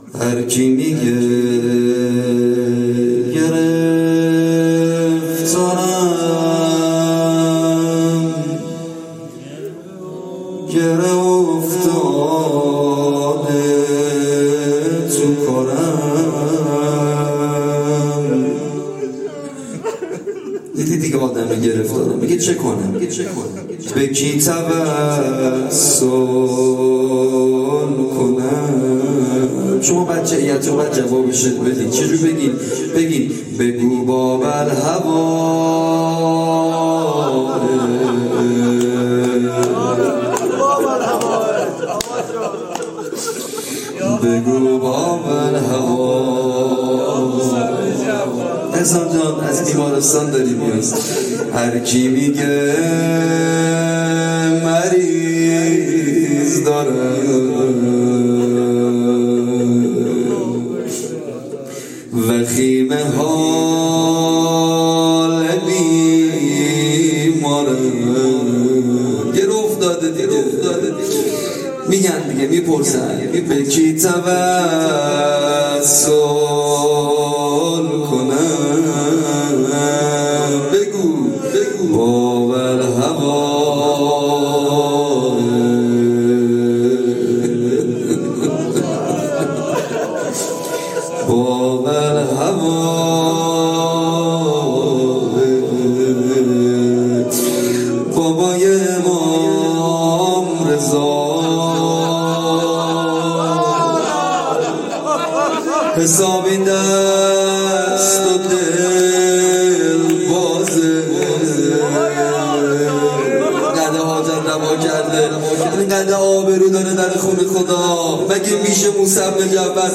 شهادت امام کاظم علیه السلام ۱۴۰۱
زمزمه